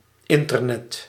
Ääntäminen
Synonyymit Internet Ääntäminen France: IPA: /ɛ̃.tɛʁ.nɛt/ Haettu sana löytyi näillä lähdekielillä: ranska Käännös Ääninäyte Substantiivit 1. internet {n} Suku: m .